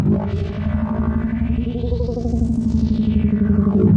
科幻小说中的未来主义声音 " 空间声音禅 3
描述：电影中的科幻科幻外星人，适合作为背景或电影
标签： 机械 科幻 科幻 未来 外星人
声道立体声